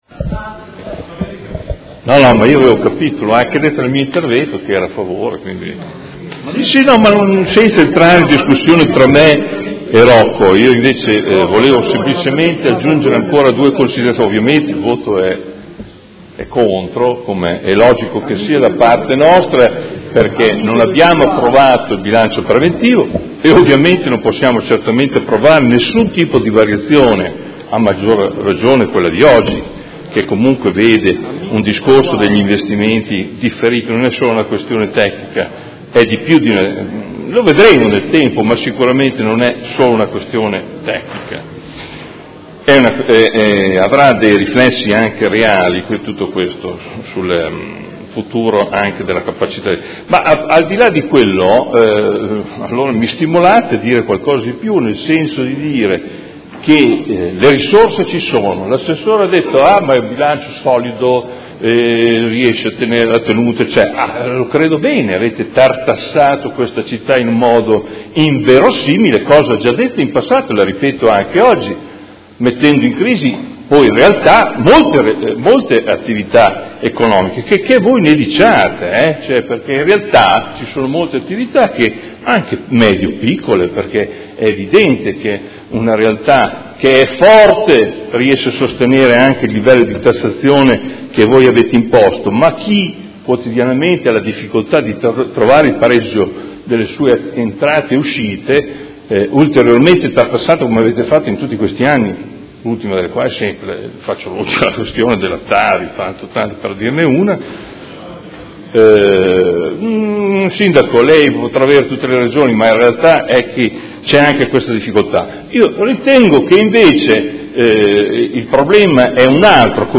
Dichiarazione di voto
Audio Consiglio Comunale